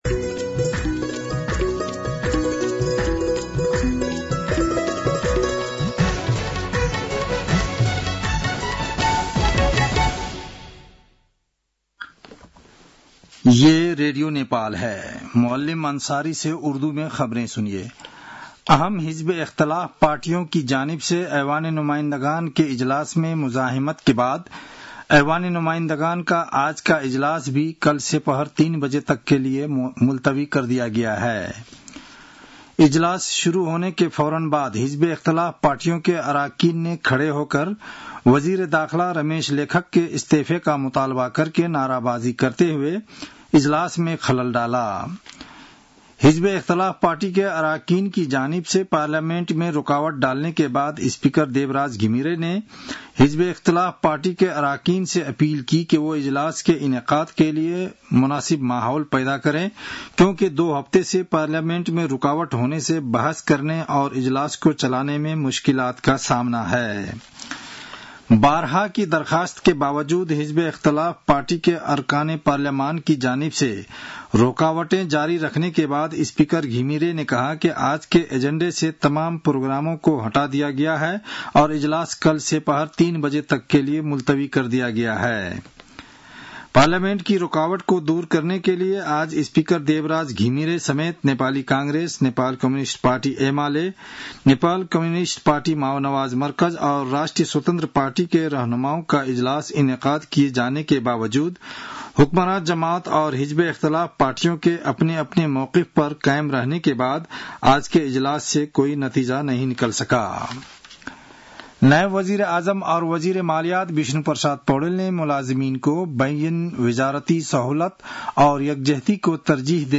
An online outlet of Nepal's national radio broadcaster
उर्दु भाषामा समाचार : २८ जेठ , २०८२